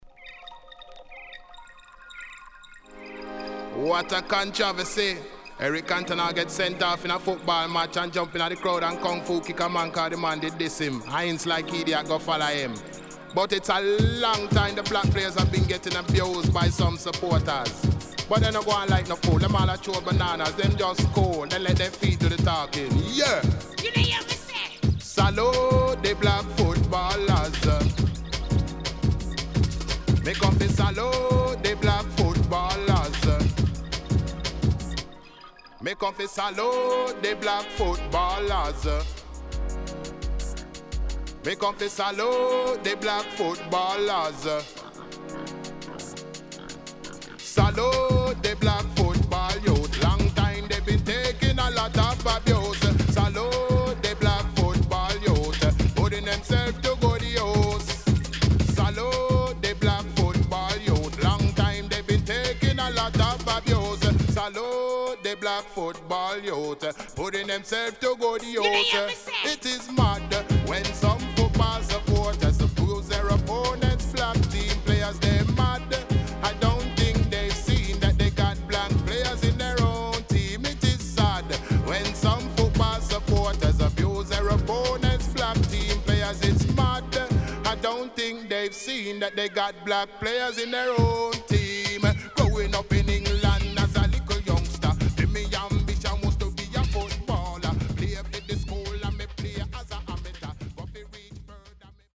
軽快なNice UK Dancehall.W-Side Good.Good Condition